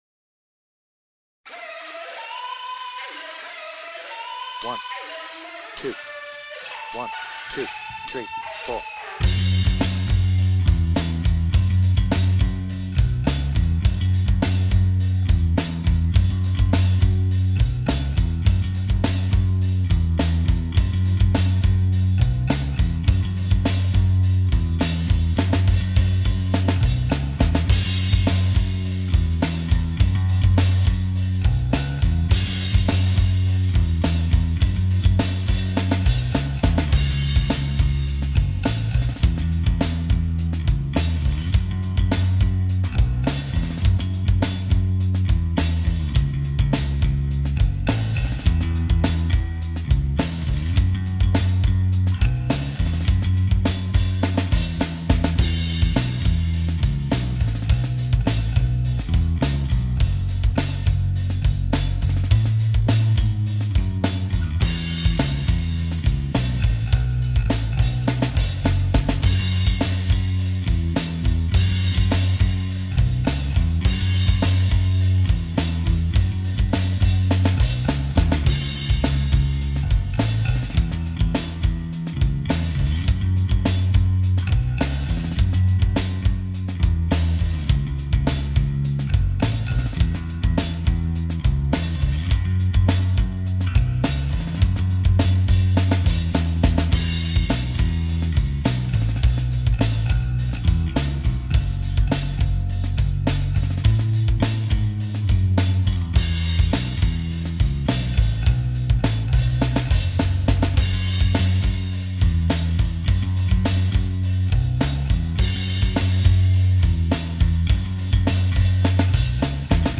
BPM : 104
Without vocals